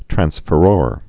(trănsfə-rôr)